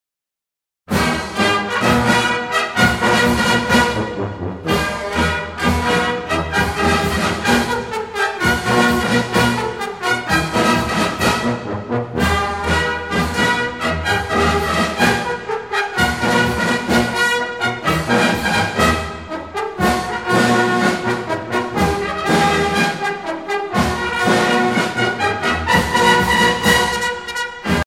circonstance : militaire
Pièce musicale éditée